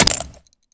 grenade_hit_02.WAV